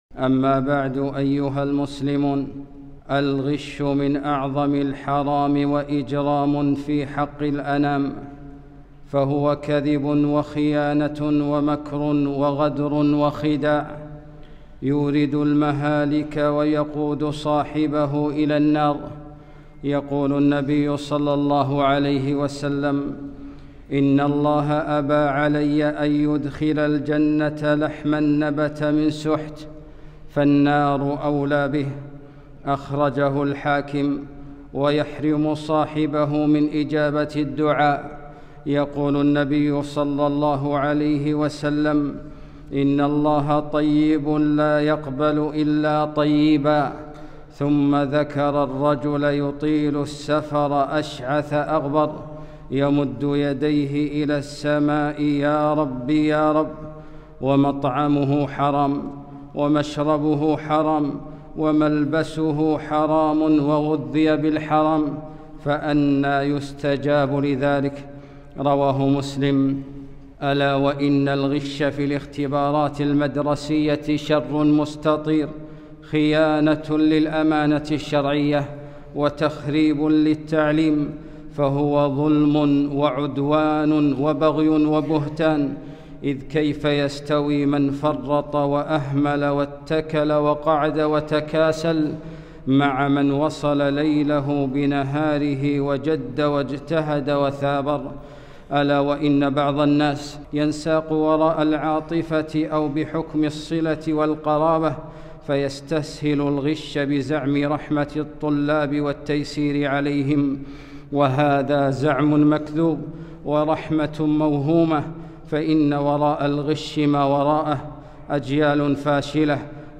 خطبة - الغش في الاختبارات